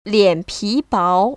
[liănpí báo] 리앤피바오  ▶